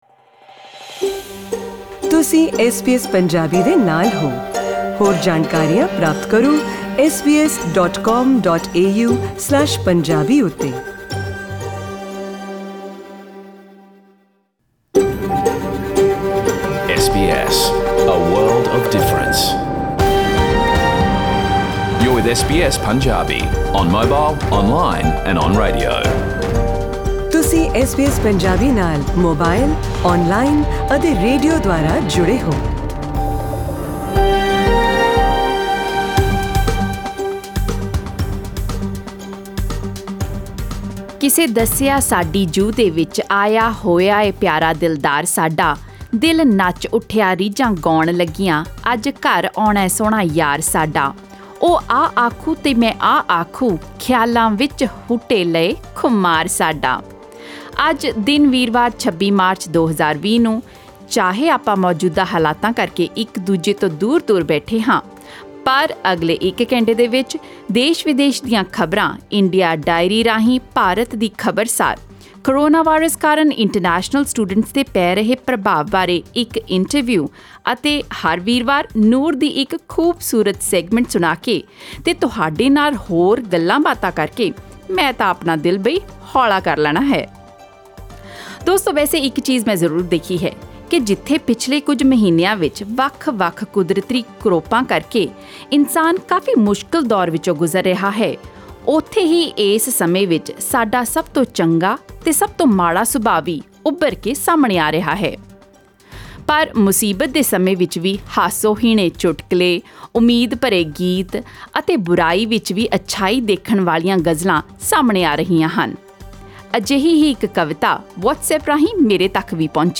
Australian News in Punjabi: 26 March 2020